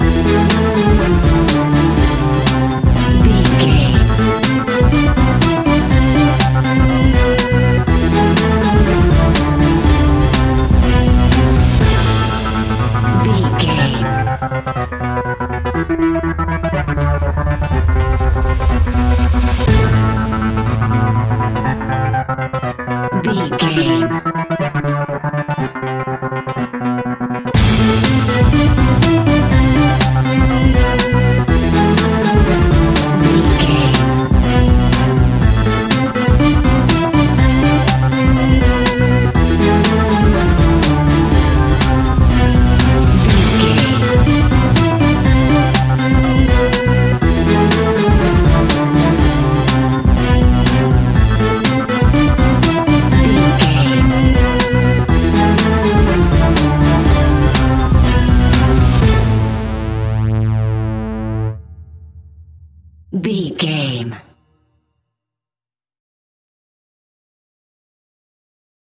Aeolian/Minor
scary
ominous
dark
fun
haunting
eerie
groovy
funky
electric organ
synthesiser
drums
strings
percussion
spooky
horror music